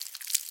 Minecraft Version Minecraft Version 1.21.5 Latest Release | Latest Snapshot 1.21.5 / assets / minecraft / sounds / mob / silverfish / step3.ogg Compare With Compare With Latest Release | Latest Snapshot
step3.ogg